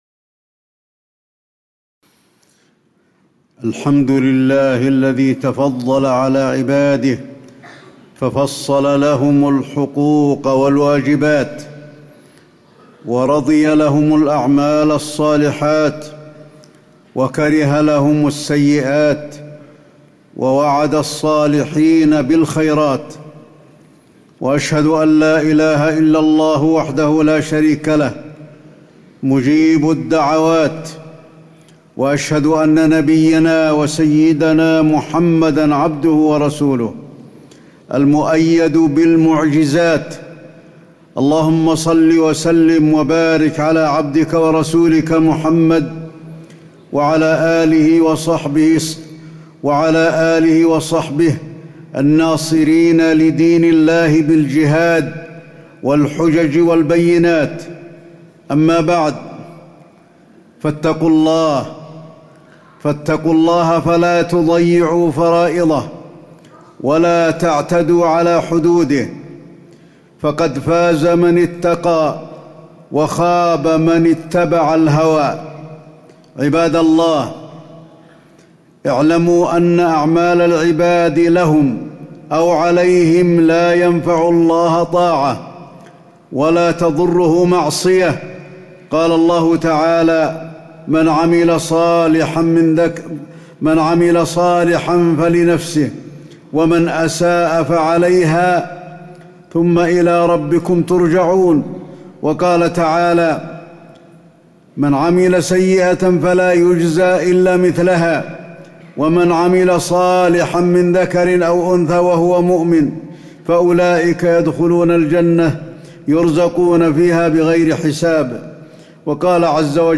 تاريخ النشر ٢٧ جمادى الأولى ١٤٣٨ هـ المكان: المسجد النبوي الشيخ: فضيلة الشيخ د. علي بن عبدالرحمن الحذيفي فضيلة الشيخ د. علي بن عبدالرحمن الحذيفي حقوق الوالدين